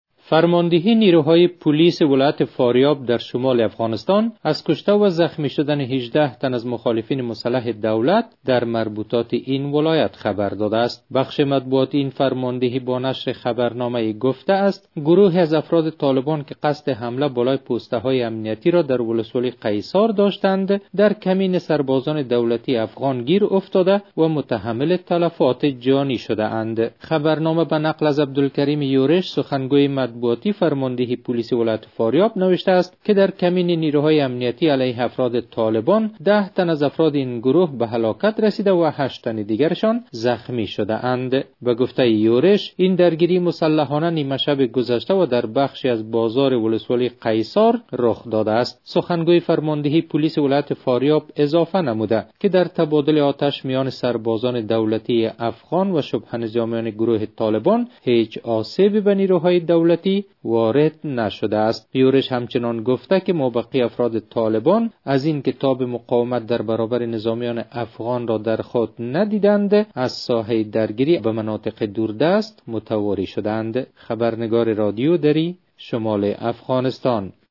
جزئیات بیشتر در گزارش